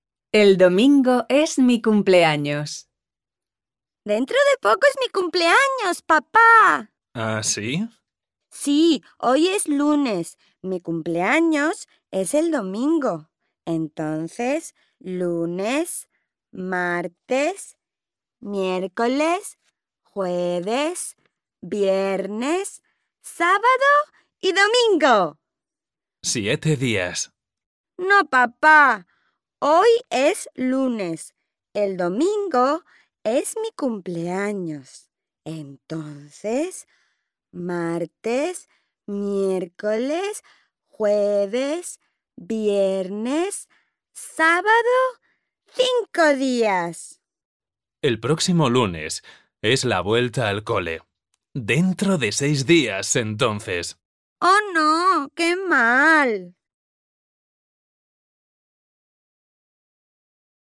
Listen to the story 'El domingo es mi cumpleaños' performed by Spanish speakers